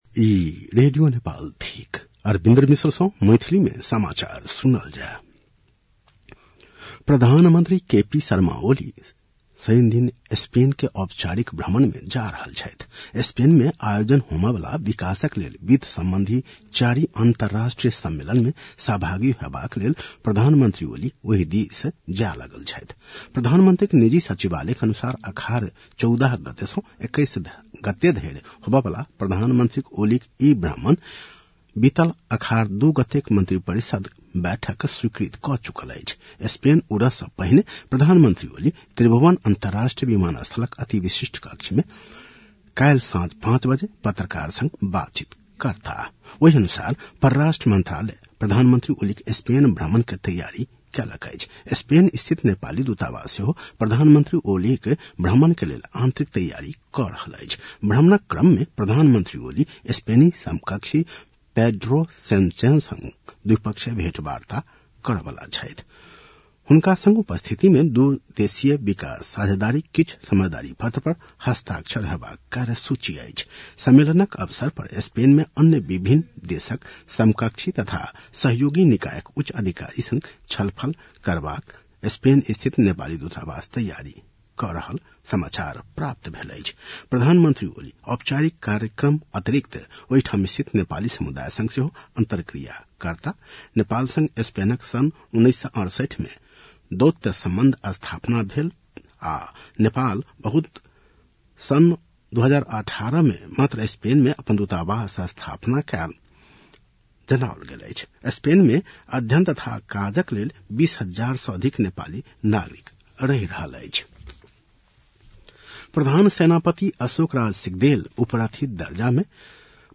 मैथिली भाषामा समाचार : १३ असार , २०८२